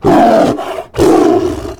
prepare1.ogg